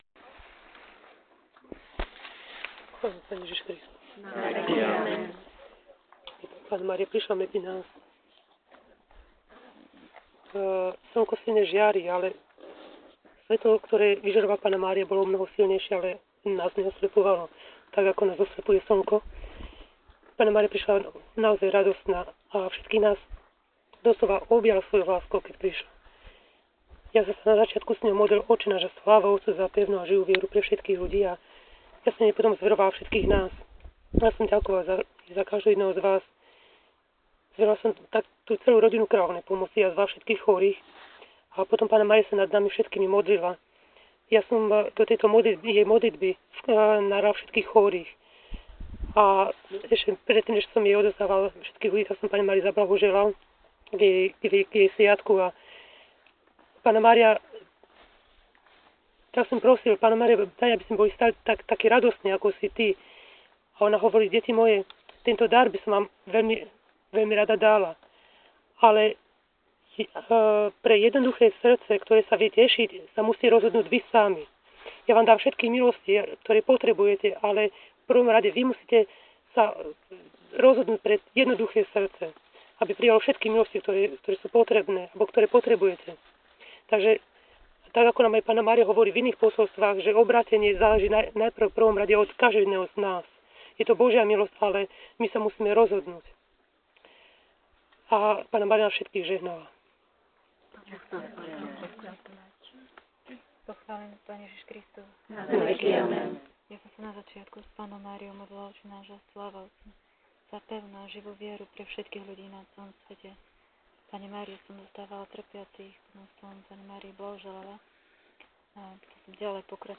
zvukový záznam z Dechtíc 5. augusta 2011 TU